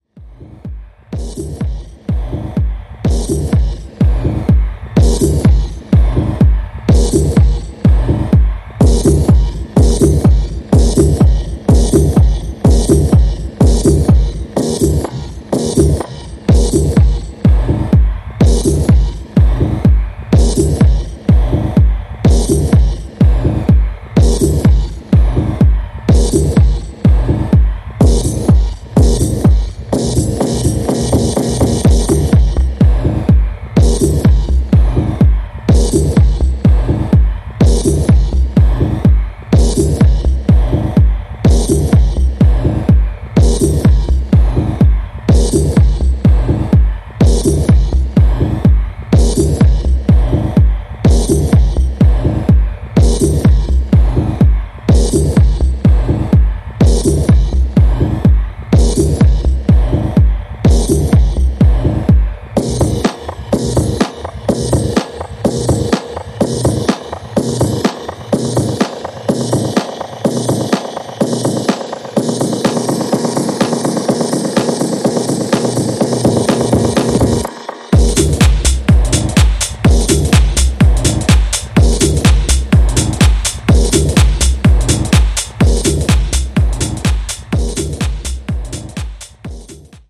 ジャンル(スタイル) TECH HOUSE / DEEP HOUSE / MINIMAL